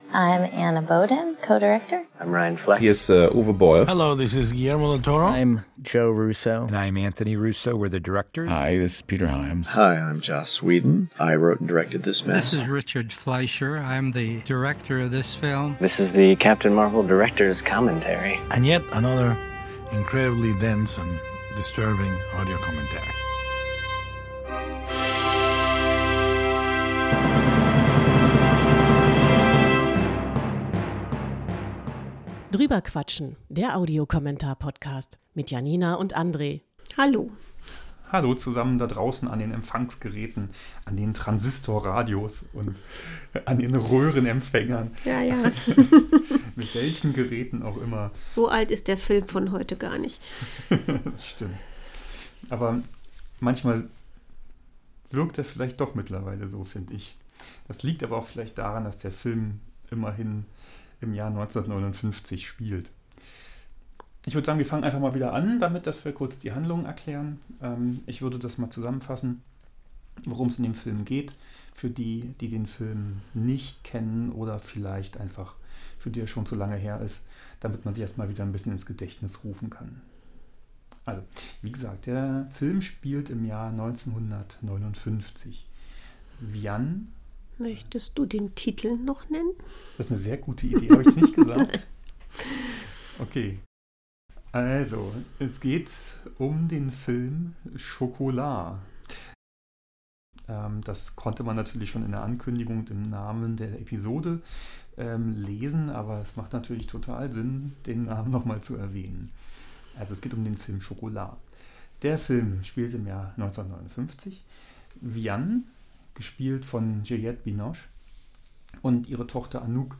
Chocolat, wir reden über den Film und den Audiokommentar, zusammengeschnitten aus zwei verschiedenen Spuren. Regisseur und Produzenten äußern sich.